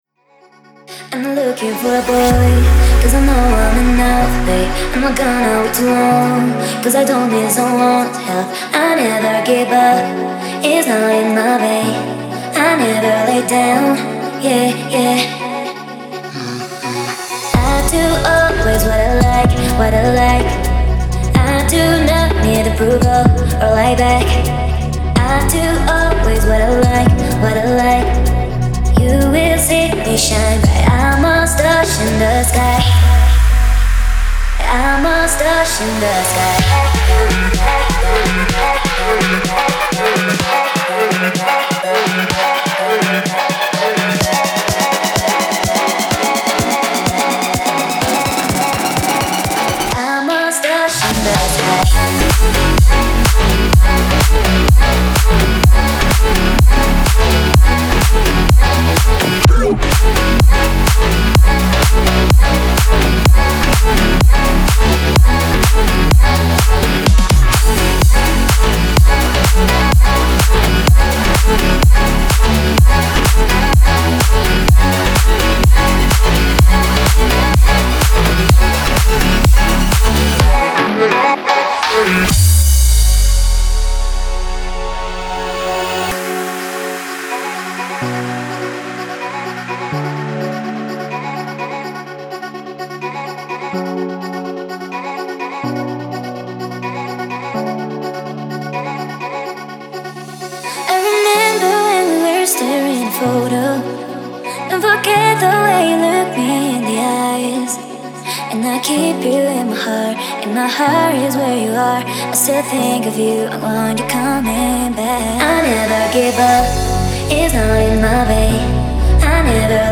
выполненная в жанре электро-поп.